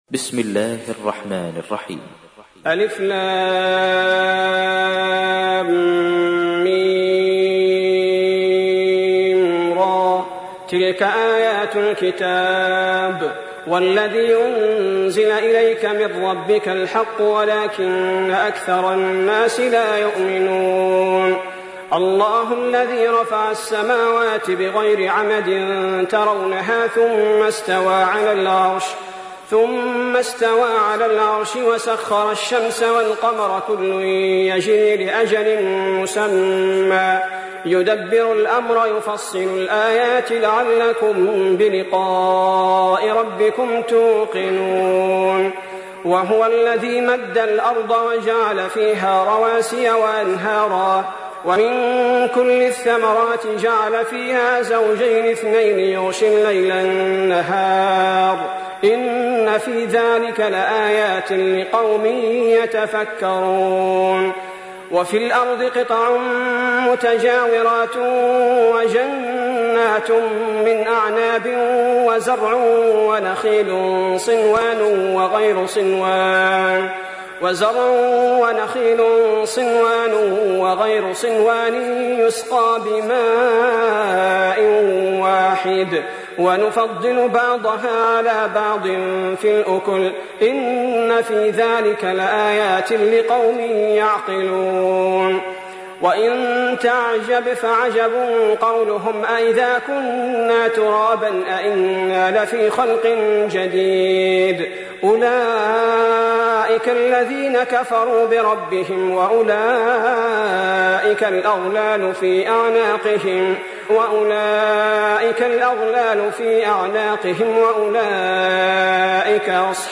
تحميل : 13. سورة الرعد / القارئ صلاح البدير / القرآن الكريم / موقع يا حسين